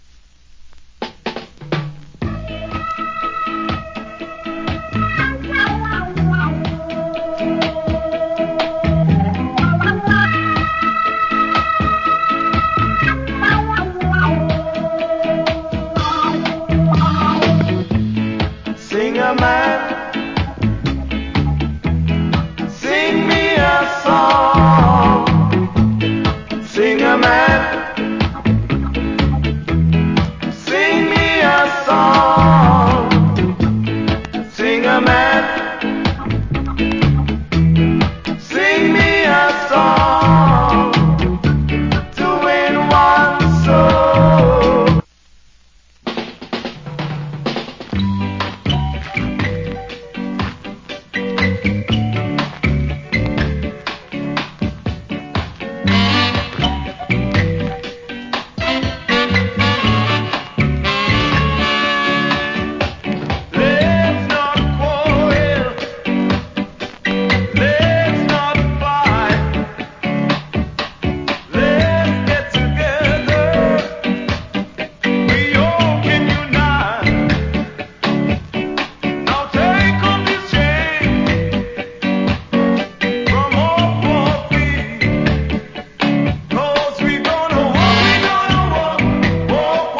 Nice Early Reggae.